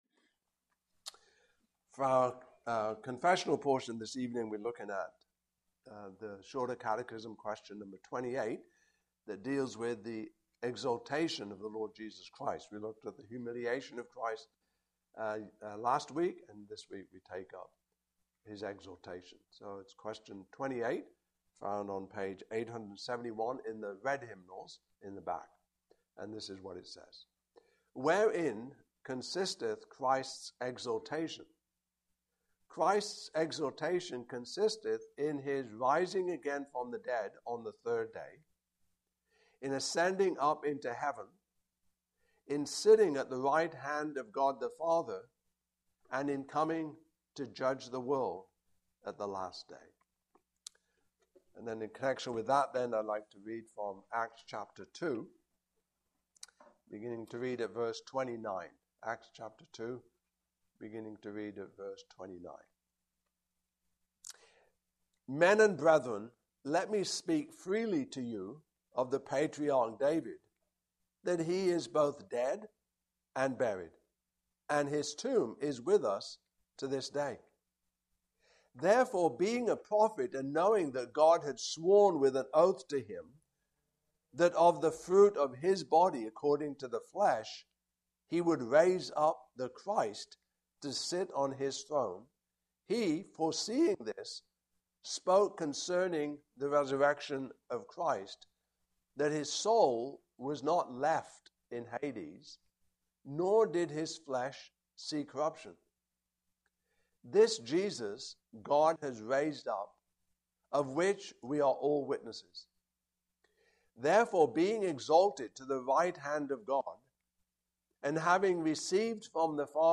Westminster Shorter Catechism Passage: Acts 2:29-41 Service Type: Evening Service Topics